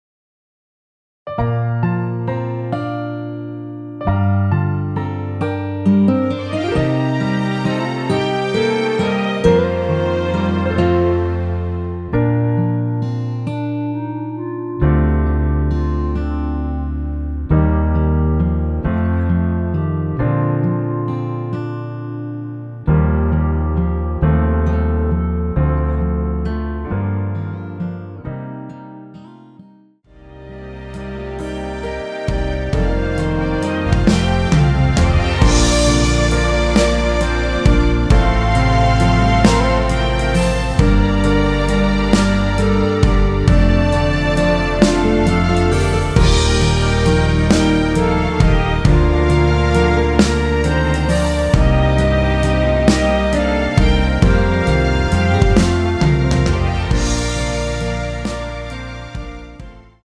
멜로디 MR입니다.
원키에서(-2)내린 멜로디 포함된 MR입니다.
Bb
멜로디 MR이라고 합니다.
앞부분30초, 뒷부분30초씩 편집해서 올려 드리고 있습니다.